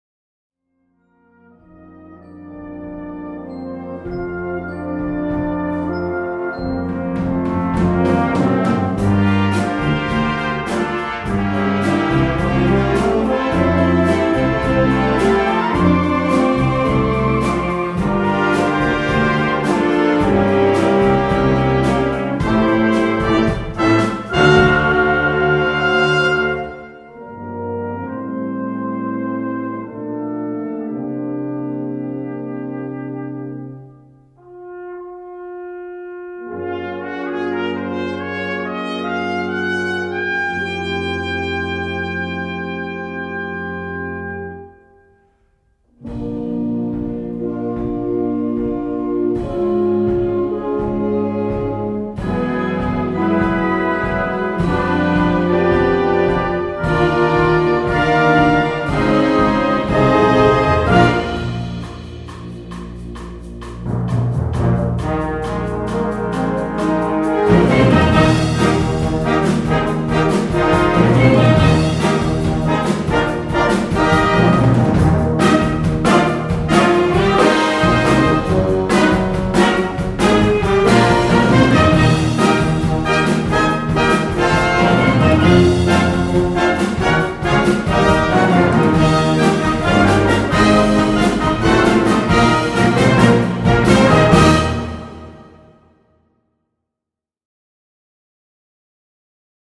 Gattung: Latin Rock
Besetzung: Blasorchester